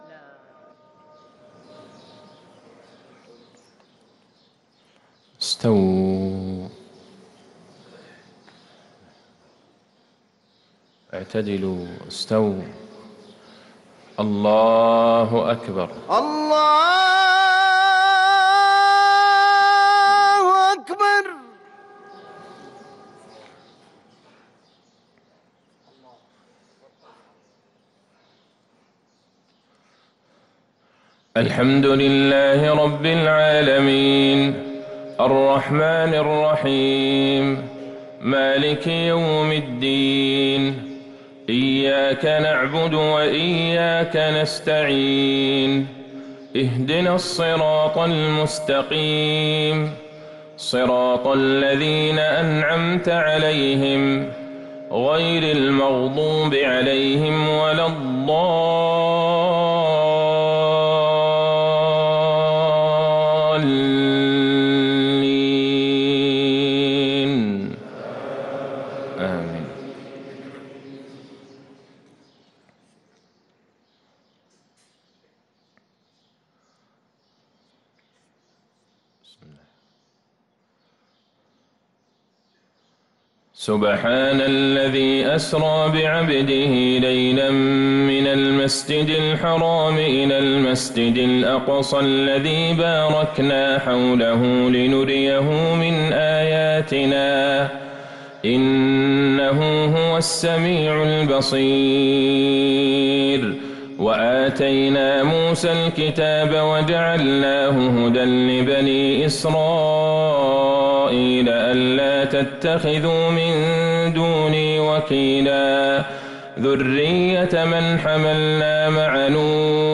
صلاة الفجر للقارئ عبدالله البعيجان 3 جمادي الآخر 1445 هـ
تِلَاوَات الْحَرَمَيْن .